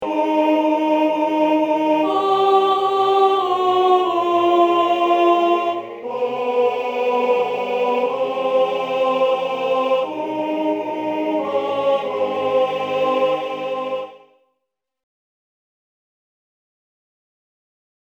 Key written in: A♭ Major
How many parts: 4